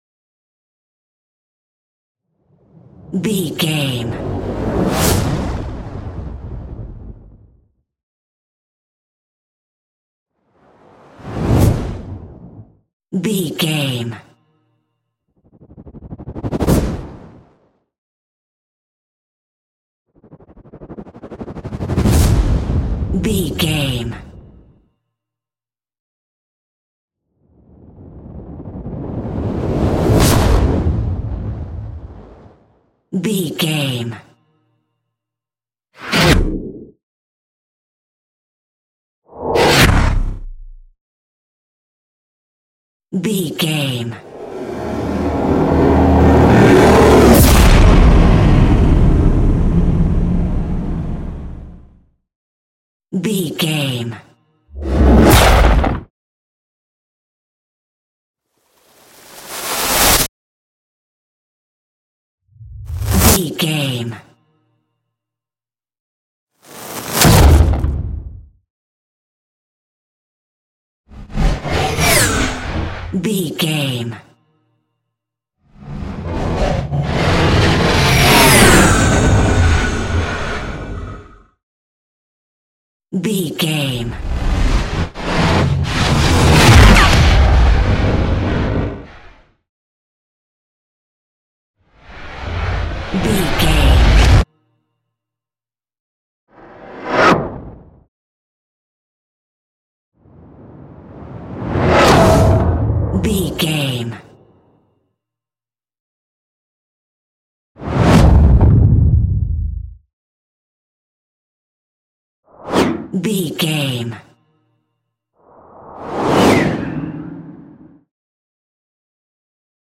Trailer Whoosh Collection 1 x23
Sound Effects
Fast paced
In-crescendo
Atonal
futuristic
high tech
intense
whoosh